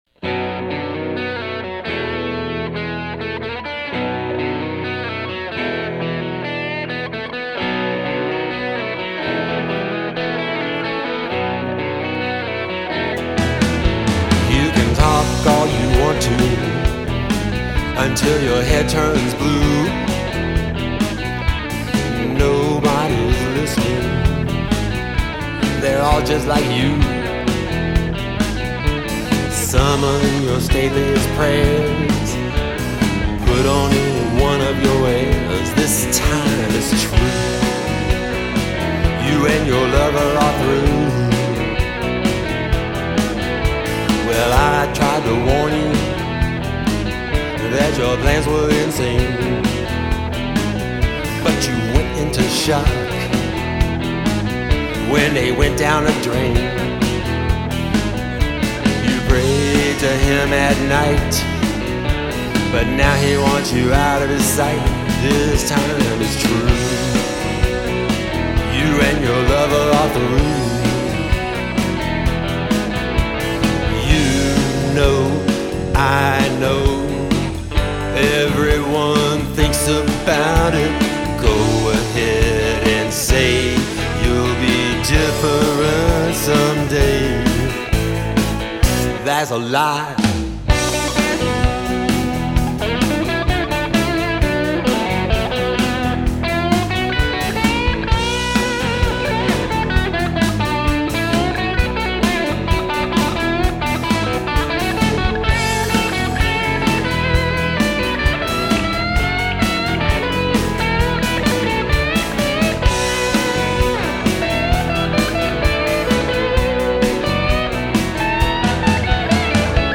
stereo, 3.87MB(mp3)